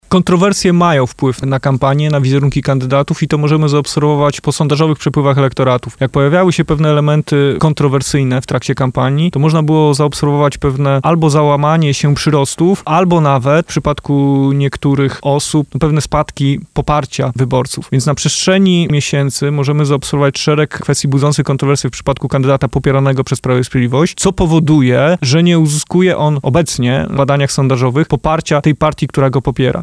politolog i medioznawca.